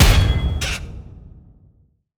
rank-impact-fail-d.wav